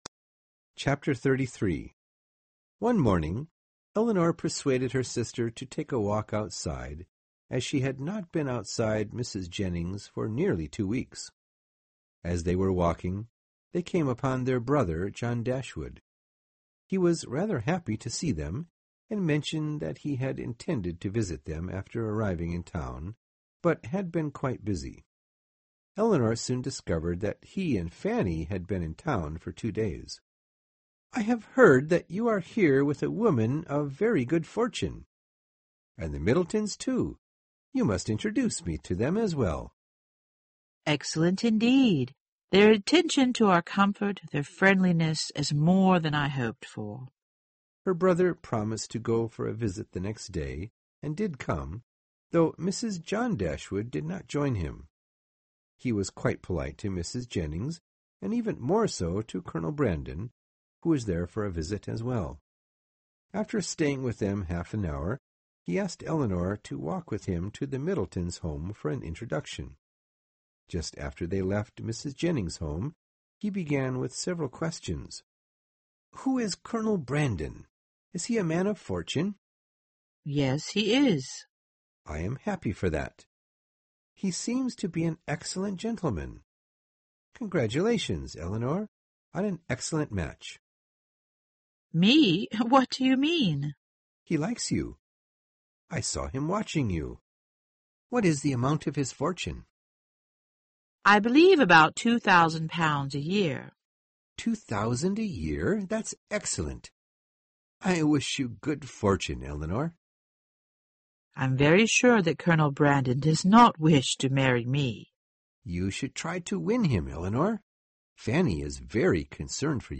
有声名著之理智与情感 33-34 听力文件下载—在线英语听力室